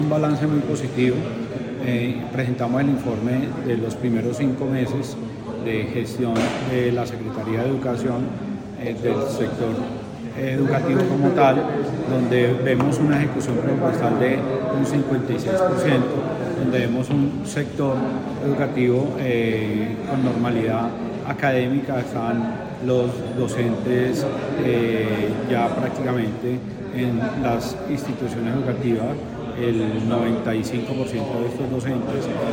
AUDIO-SECRETARIO-DE-EDUCACION-EN-CONCEJO.mp3